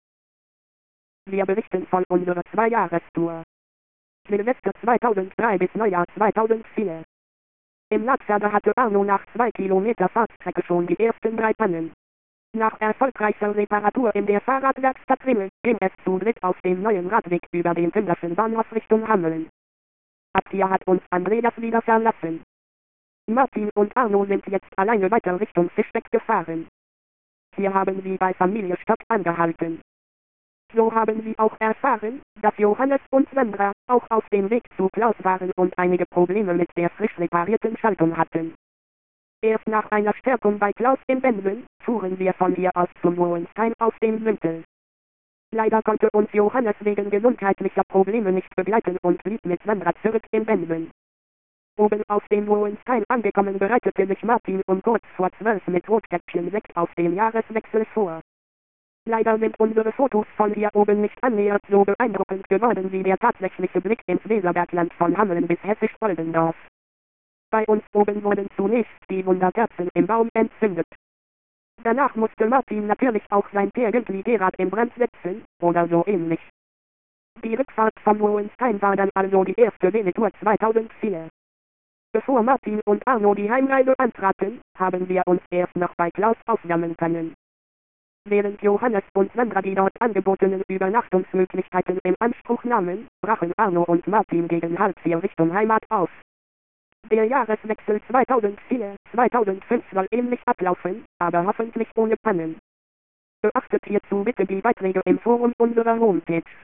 (gekürzter Bericht, schneller gesprochen, schlechtere Tonqualität)